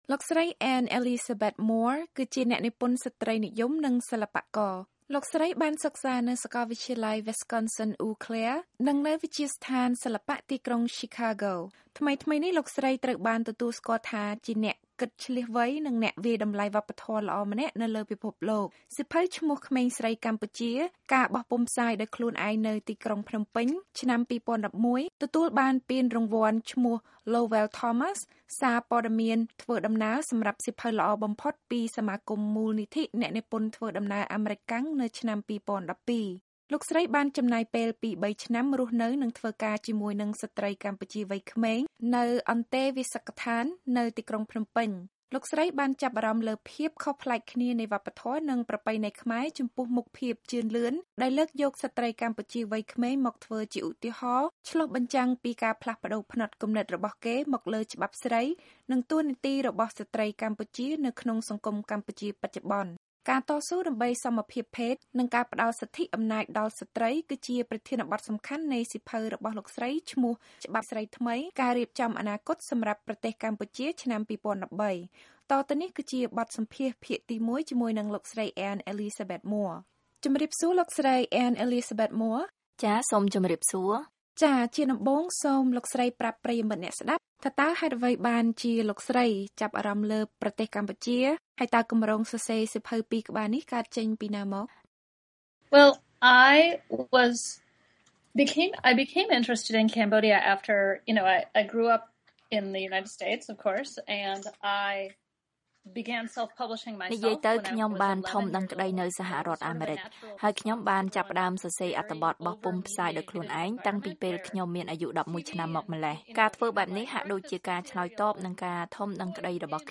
បទសម្ភាសជាមួយស្រ្តីសិល្បករ និងអ្នកនិពន្ធអាមេរិកាំងនិយាយអំពីស្ត្រីកម្ពុជាកំពុងលេចធ្លោ (ភាគ១)